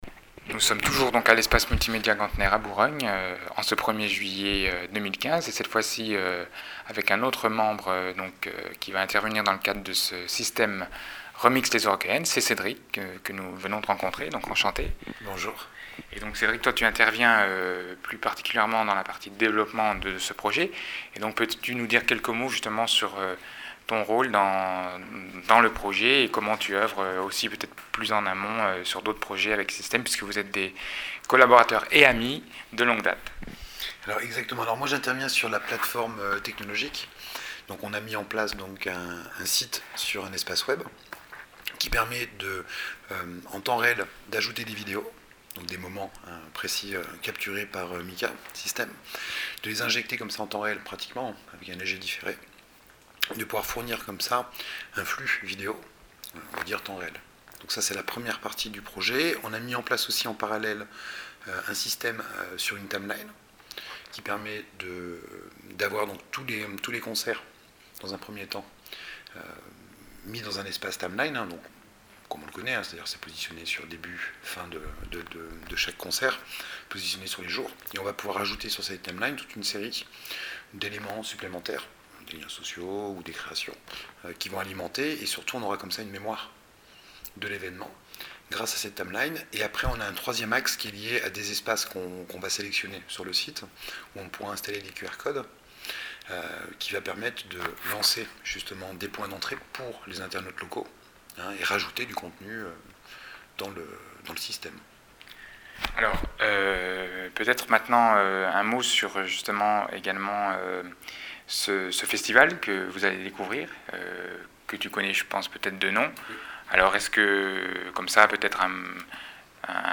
.Texte et Entretien